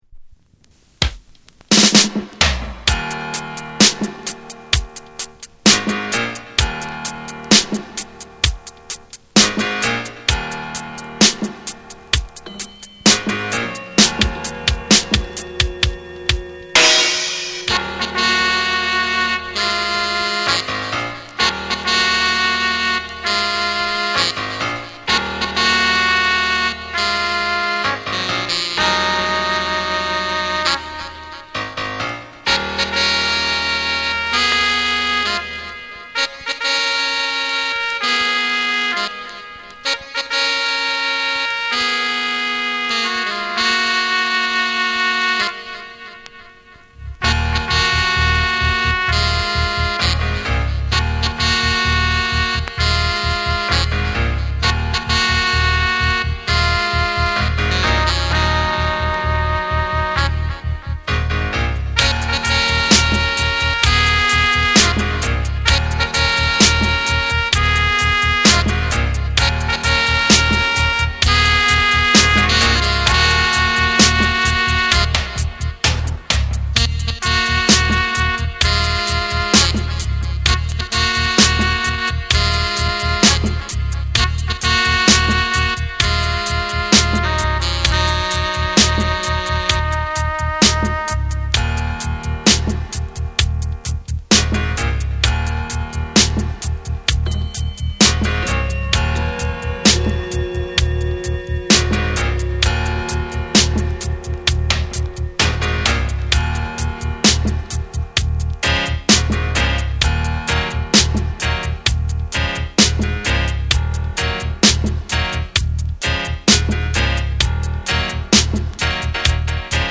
重厚なベースラインと空間的なエフェクトが広がるディープなダブを展開。
REGGAE & DUB